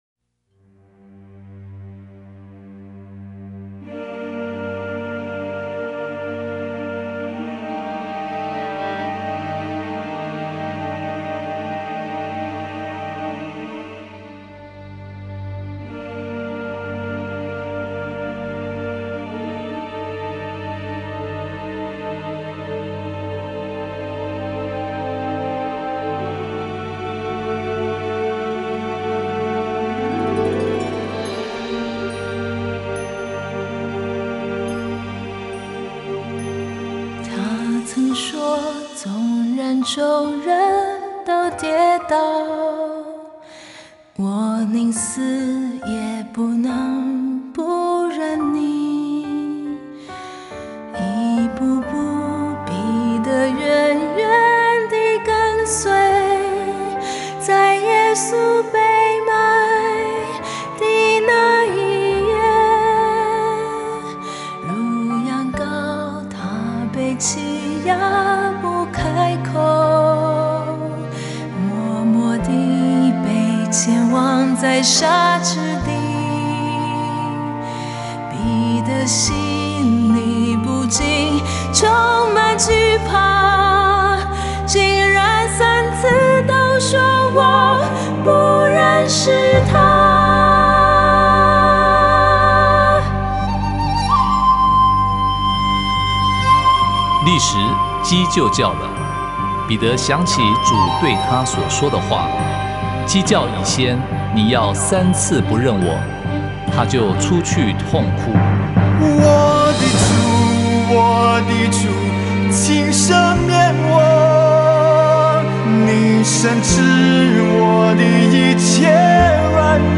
mp3 原唱音樂
前奏 → 主歌 1 → 主歌 2 → 旁白 1 → 前副歌 → 副歌(兩遍) → 旁白 2 → 副歌 → 副歌後半